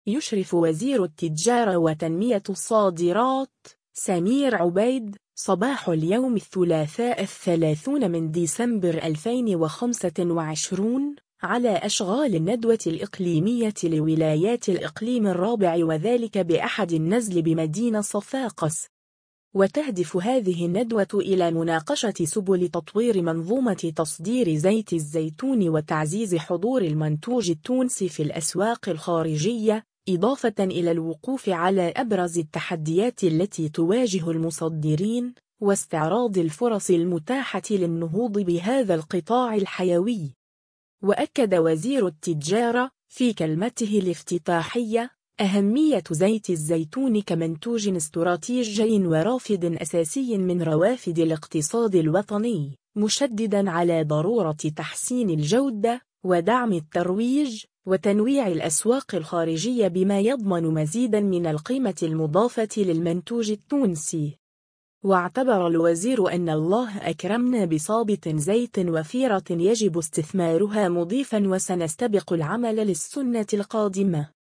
يشرف وزير التجارة وتنمية الصادرات، سمير عبيد، صباح اليوم الثلاثاء 30 ديسمبر 2025، على أشغال الندوة الإقليمية لولايات الإقليم الرابع وذلك بأحد النزل بمدينة صفاقس.
وأكد وزير التجارة، في كلمته الافتتاحية، أهمية زيت الزيتون كمنتوج استراتيجي ورافد أساسي من روافد الاقتصاد الوطني، مشددًا على ضرورة تحسين الجودة، ودعم الترويج، وتنويع الأسواق الخارجية بما يضمن مزيدًا من القيمة المضافة للمنتوج التونسي.